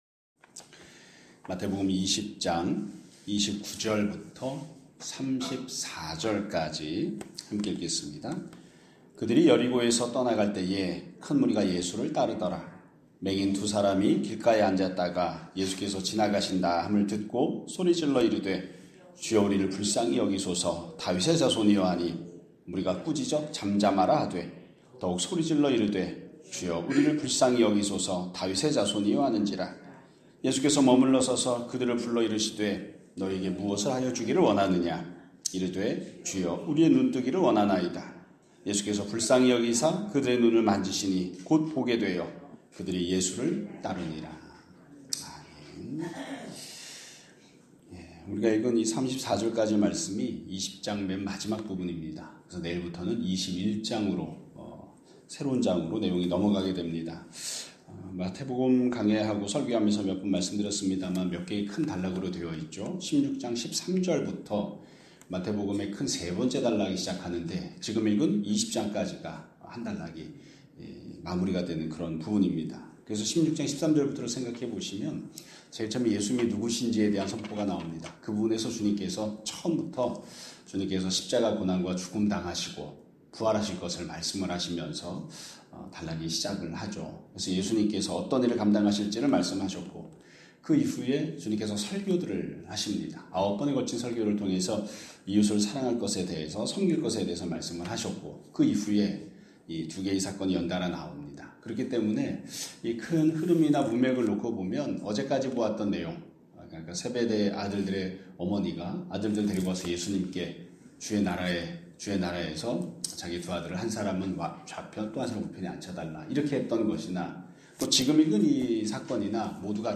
2026년 1월 20일 (화요일) <아침예배> 설교입니다.